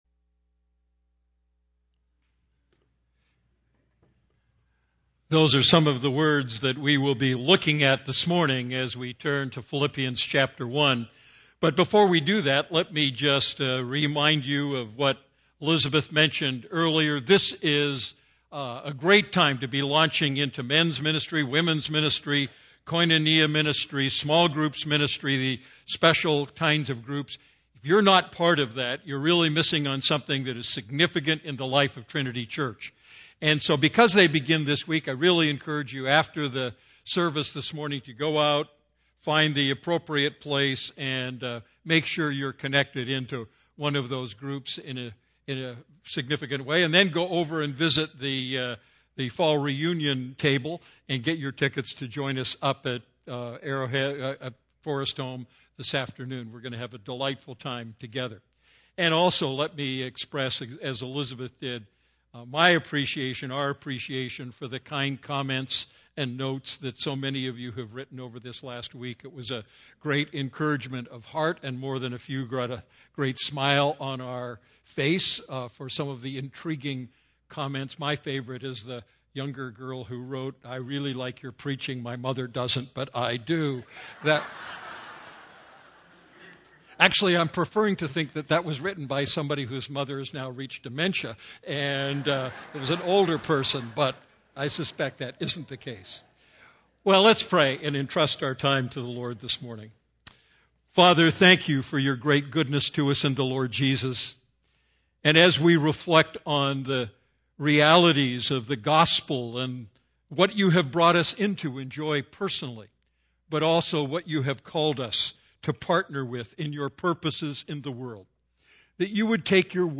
A message from the series "Going for the Gold."